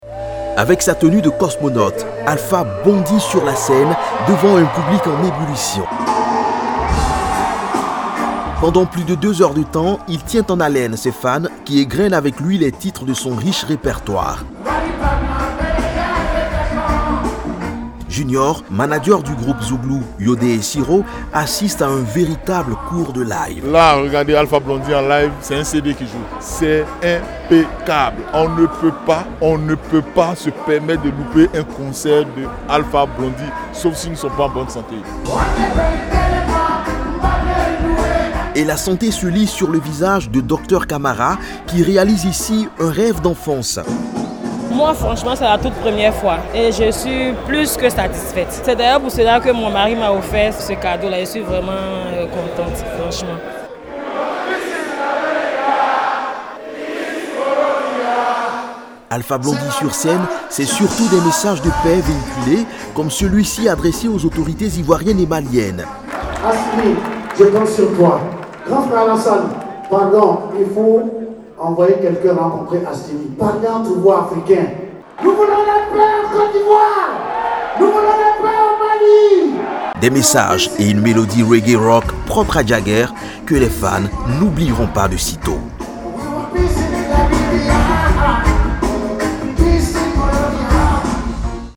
Il y était en concert live le vendredi dernier , avec son orchestre le Solaar System au grand complet.
Un concert très attendu par les fans de la star du reggae qui ont répondu massivement à l’appel.
concert-alpha-blondy.mp3